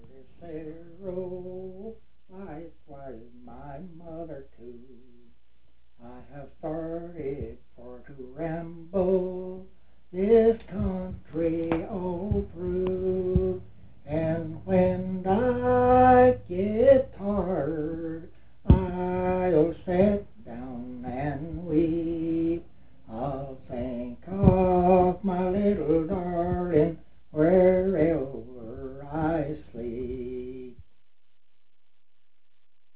fiddle and banjo